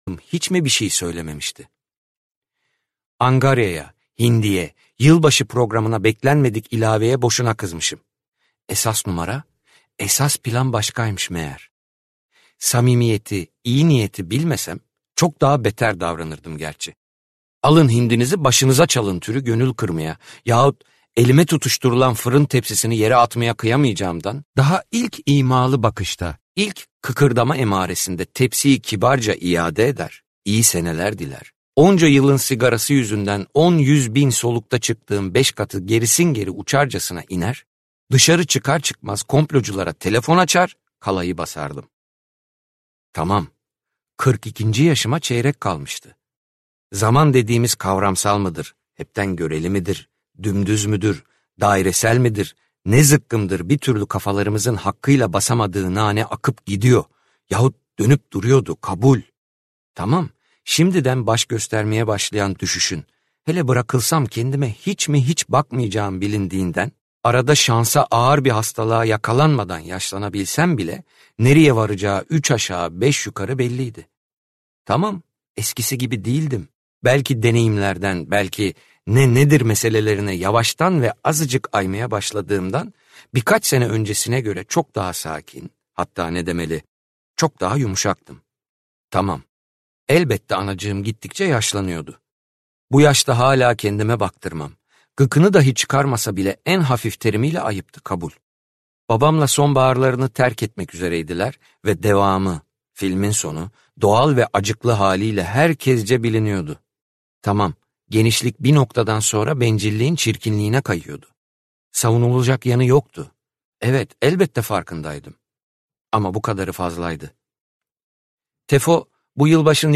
Katilin Şahidi - Seslenen Kitap